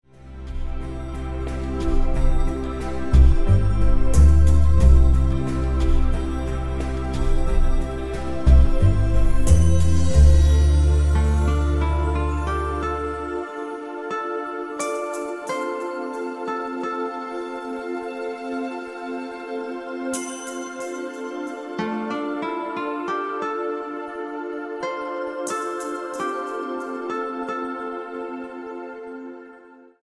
90 BPM